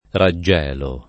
raggelo [ ra JJ$ lo ]